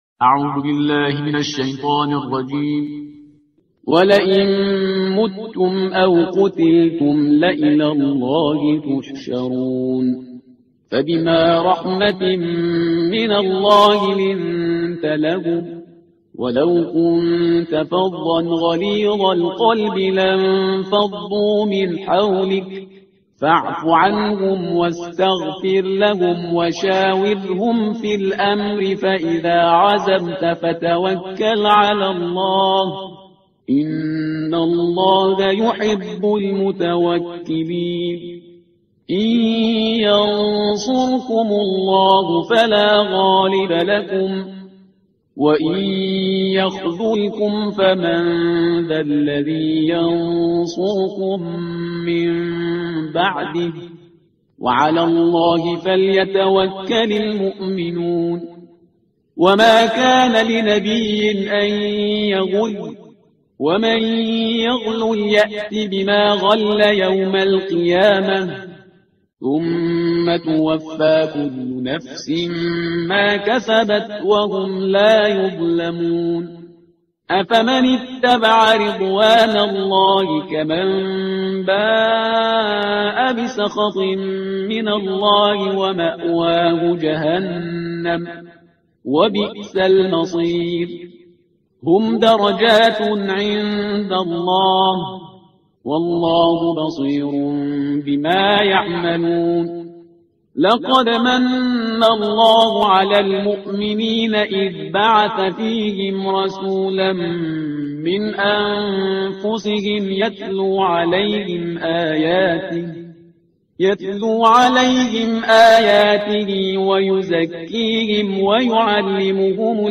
ترتیل صفحه 71 قرآن با صدای شهریار پرهیزگار